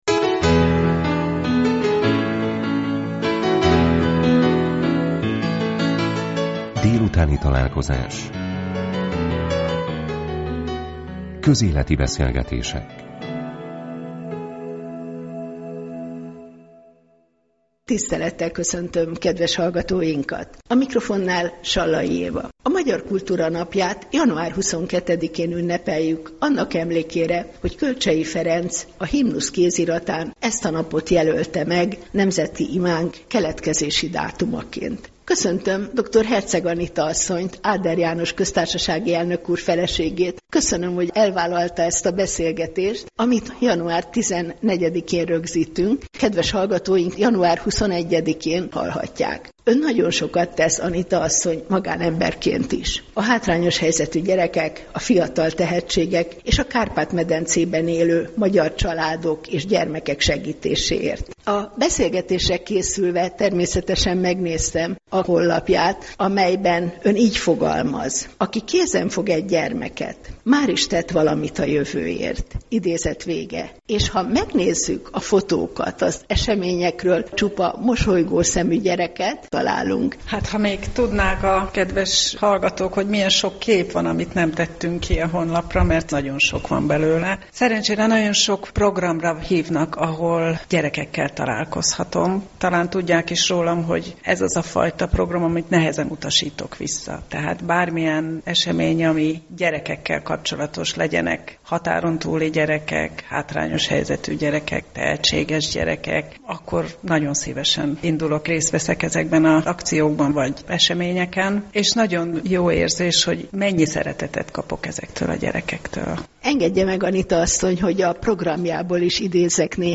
Beszélgetés a Magyar Kultúra Napján a Katolikus Rádióban
beszelgetes-a-magyar-kultura-napjan-a-katolikus-radioban-1019.mp3